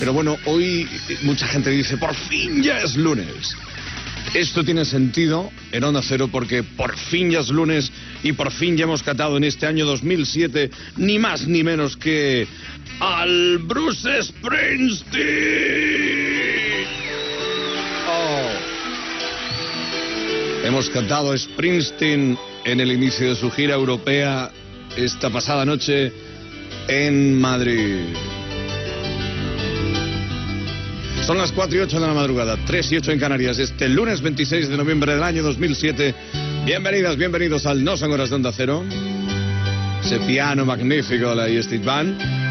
Presentació d'una cançó de Bruce Springsteen amb l'hora i la identificació del programa
Entreteniment
FM